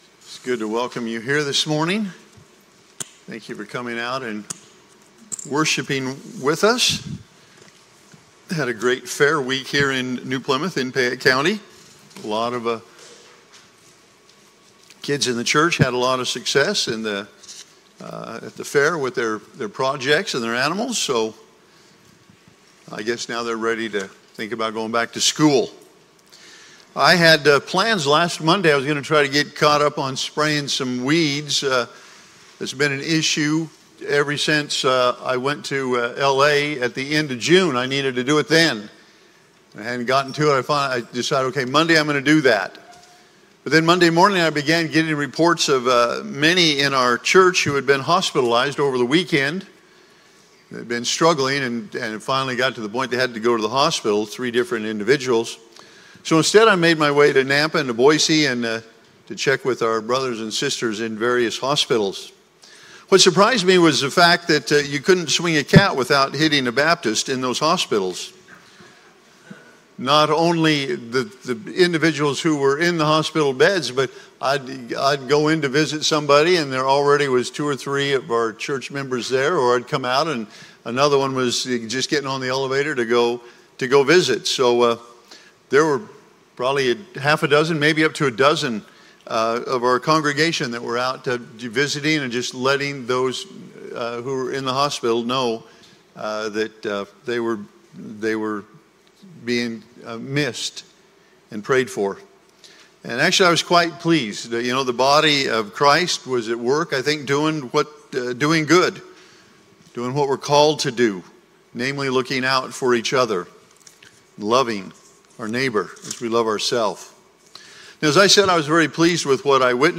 8-10-Sermon-Audio.mp3